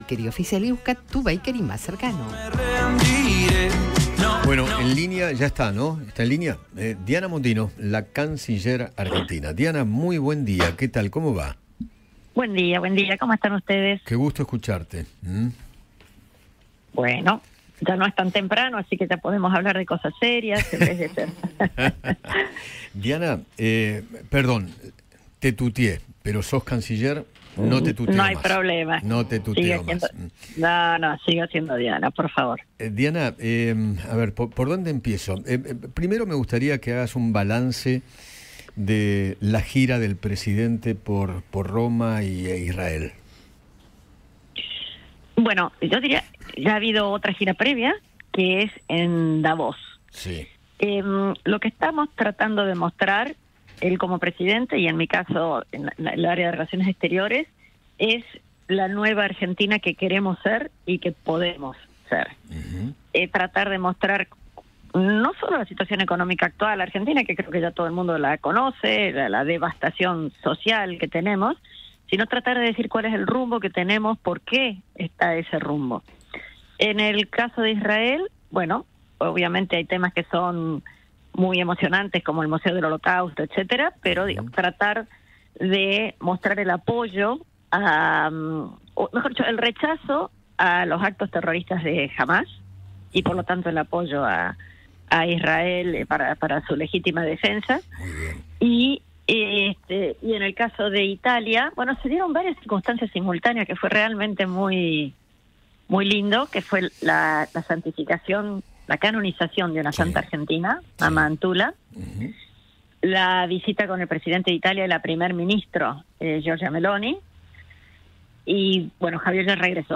Eduardo Feinmann conversó con la canciller Diana Mondino sobre la reunión con el ministro de Relaciones Exteriores británico y se refirió a las relaciones internacionales del gobierno de Javier Milei.